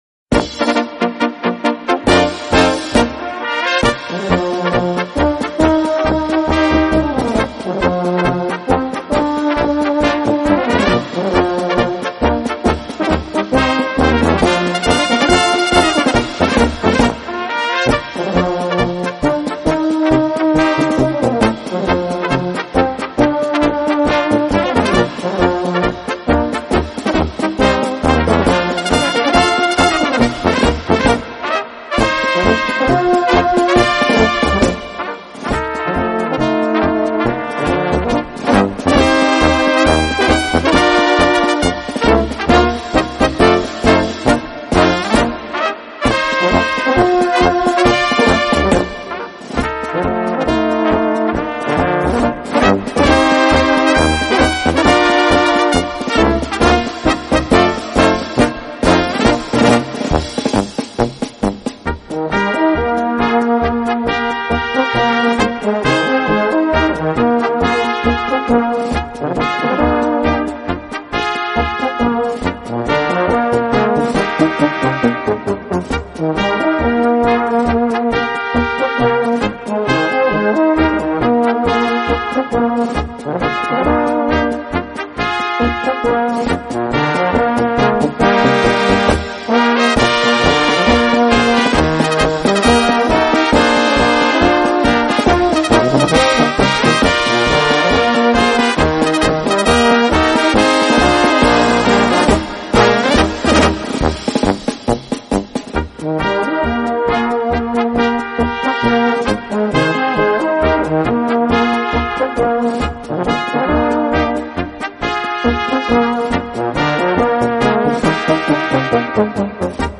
Gattung: Marsch für kleine Besetzung
Besetzung: Kleine Blasmusik-Besetzung